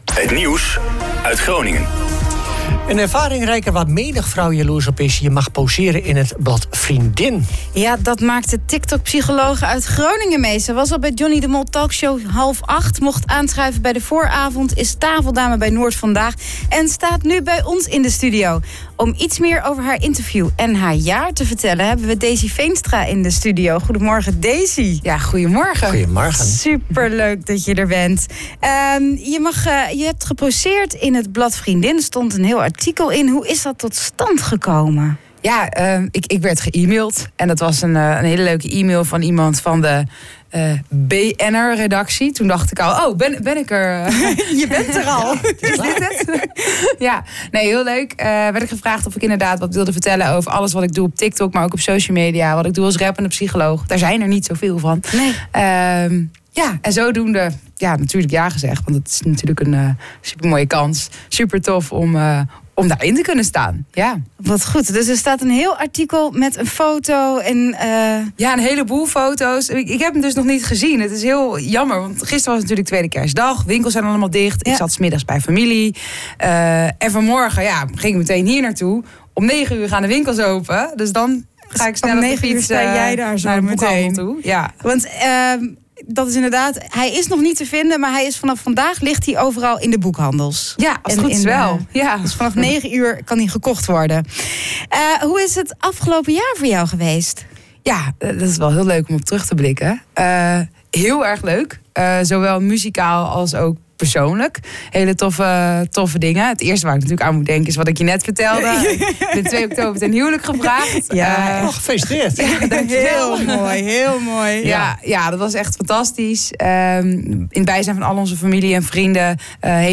En afgelopen dinsdag was ze te gast in de OOG Ochtendshow.